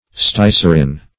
Search Result for " stycerin" : The Collaborative International Dictionary of English v.0.48: Stycerin \Sty"cer*in\, n. [Styryl + glycerin.]